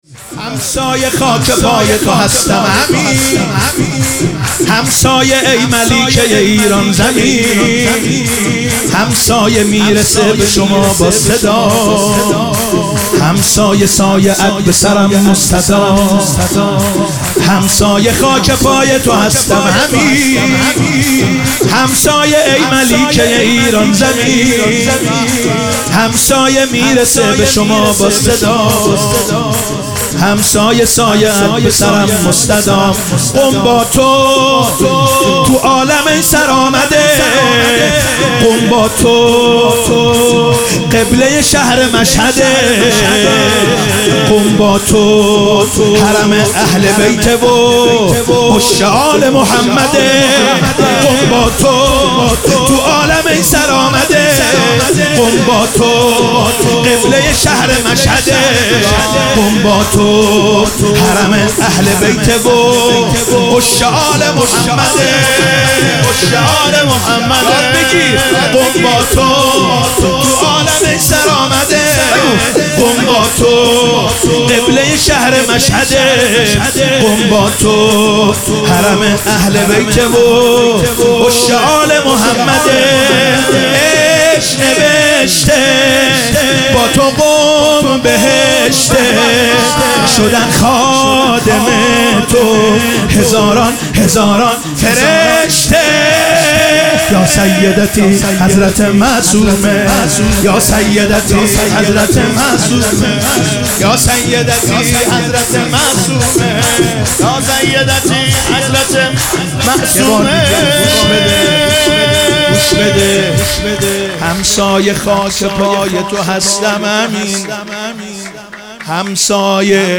با رادیو عقیق همراه شوید و مداحی همسایه خاک پای تو هستم همین را به صورت کامل بشنوید.
در مجلس هیئت رزمندگان اسلام قم
مداحی به سبک شور (جشن) اجرا شده است.